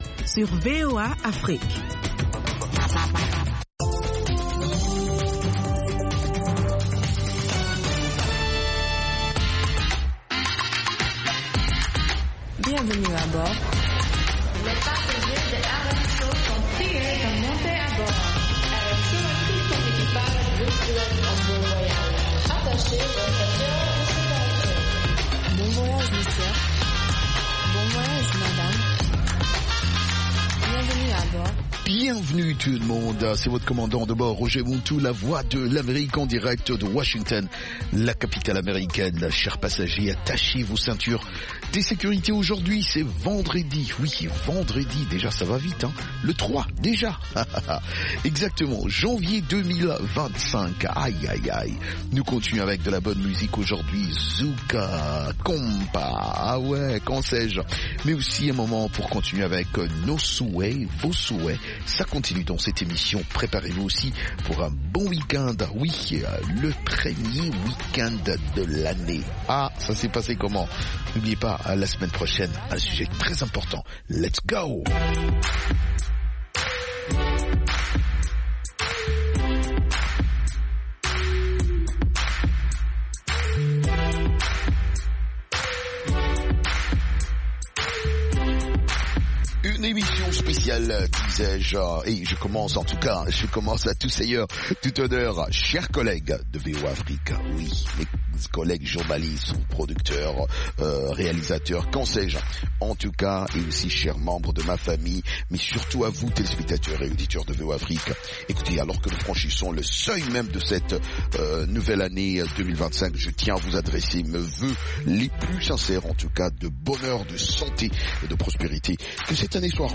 Ecoutez toute la musique des îles, Zouk, Reggae, Latino, Soca, Compas et Afro, et interviews de divers artistes